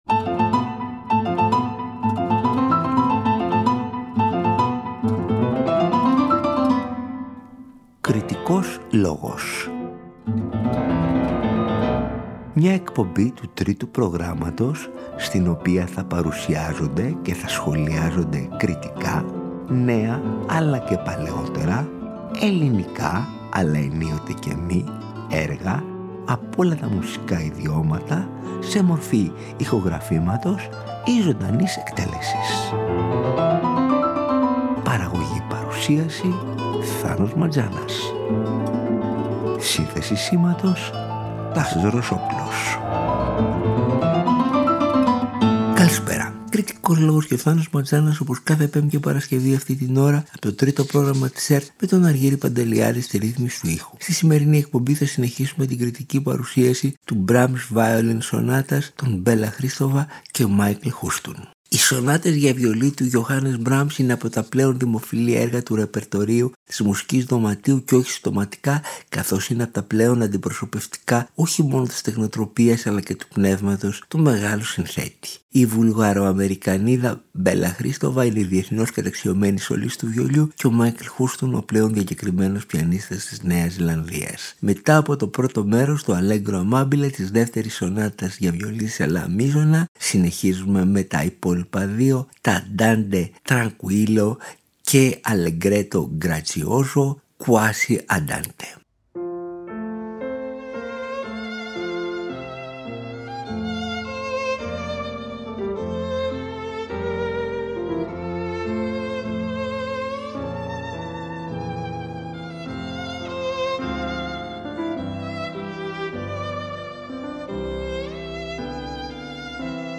σονάτες για βιολί
Η Βουλγαροαμερικανίδα διεθνώς καταξιωμένη σολίστ του βιολιού